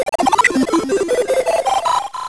shield the sound when you use a shield.